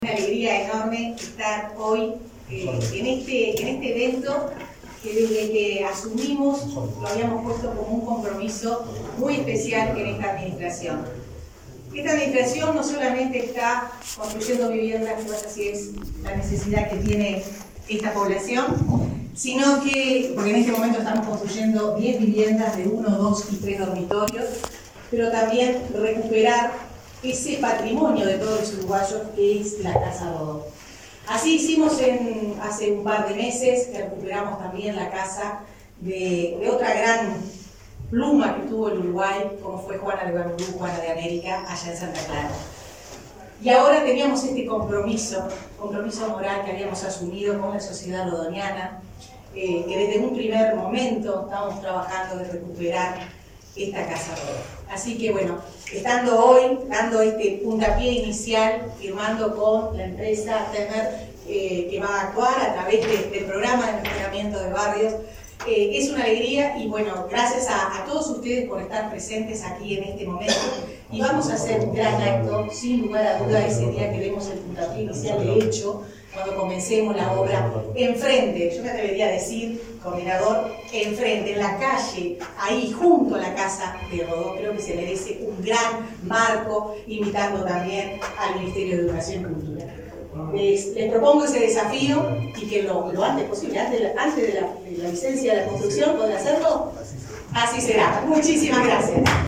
Palabras de la ministra de Vivienda, Irene Moreira